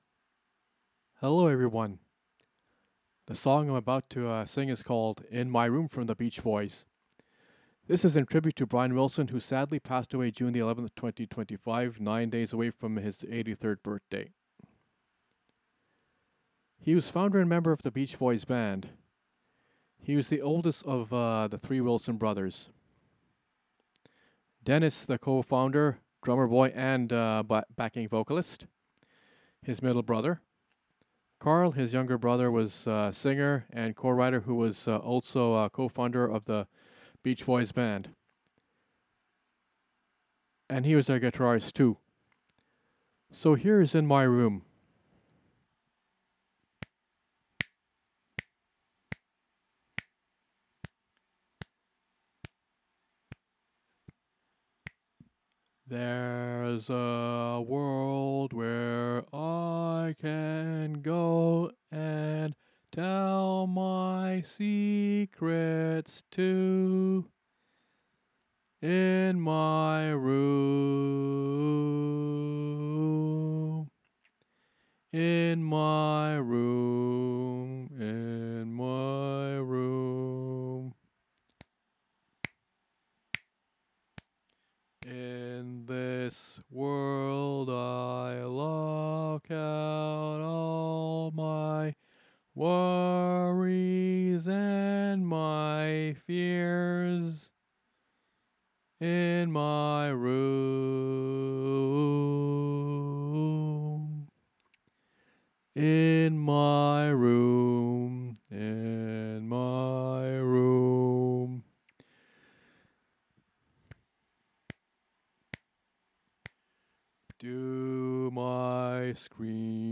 intimate and moving tribute
Have a hanky on hand cuz it’s a tear jerker…